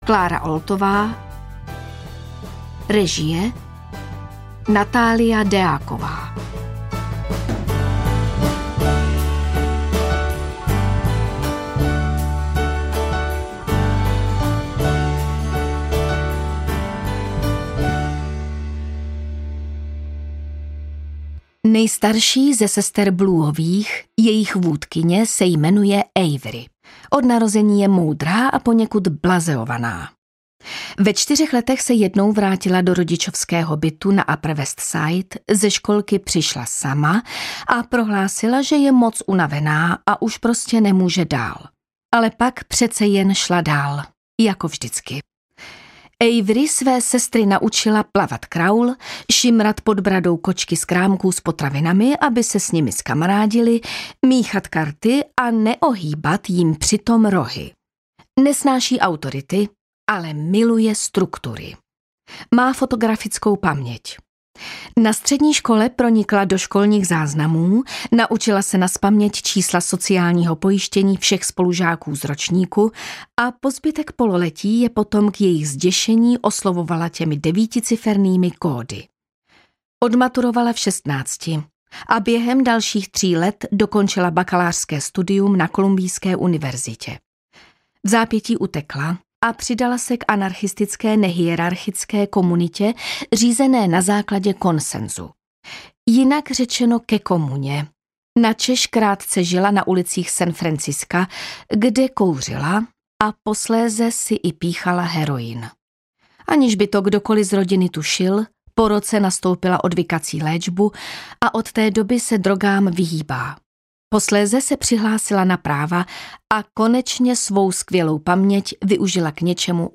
Sestry Blueovy audiokniha
Ukázka z knihy